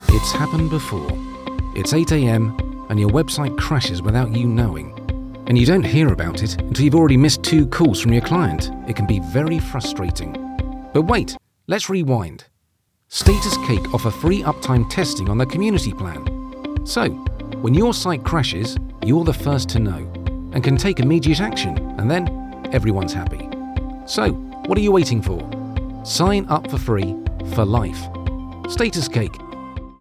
Unternehmensvideos
Warm, voll und ohne regionalen Akzent. Er arbeitet von seinem Heimstudio aus und verwendet nur die beste Ausrüstung.
Ich verwende ein Sennheiser MKH-416-Mikrofon, Audacity, Focusrite Scarlett Solo und habe ein komplett ausgestattetes Aufnahmestudio.